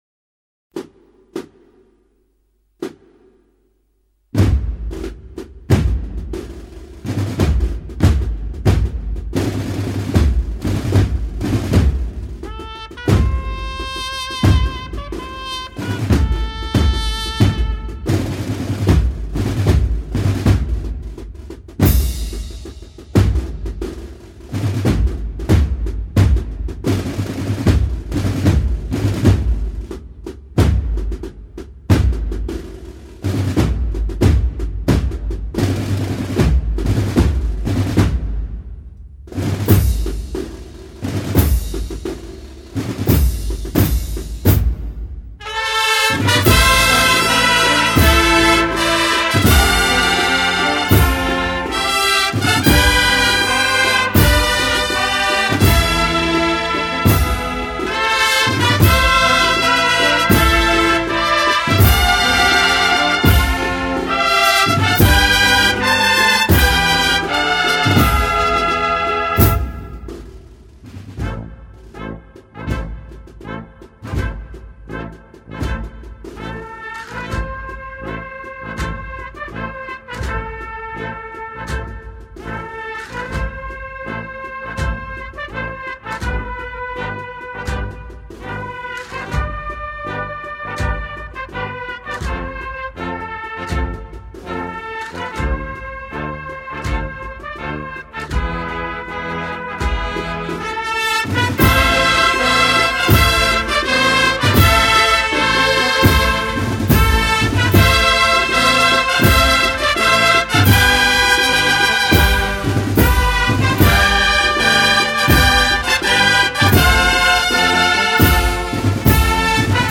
XXXIV acto de Exaltación a Nuestra Señora de la Encarnación
Otras de las sorpresas nos tenía reservada, como los acordes de la marcha «
A los piés de Sor Ángela» (2000) de Francisco Javier Álvarez,  interpretada por componentes de la Agrupación, mientras nos hablaba de las Hermanas de la Cruz o de las Hermanas Clarisas o la Plegaria «Quién te coronó Señora» a Ntra.